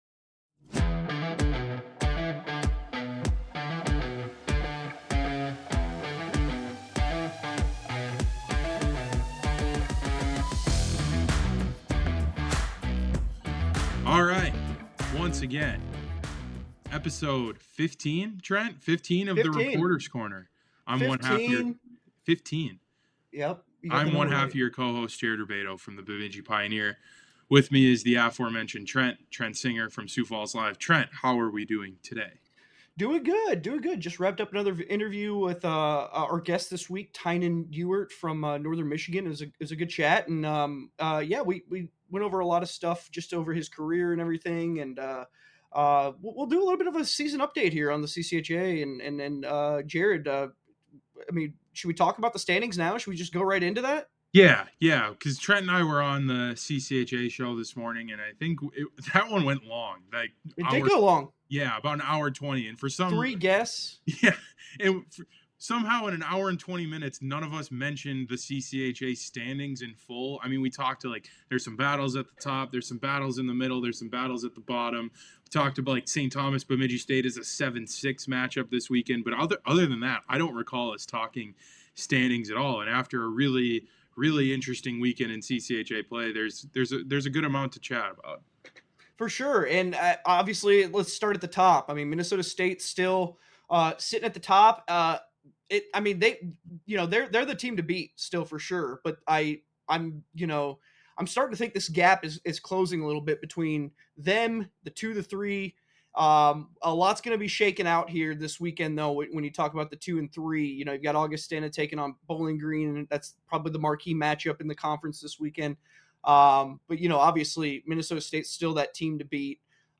Tune in weekly to hear the guys chat it up with CCHA players, coaches and staff!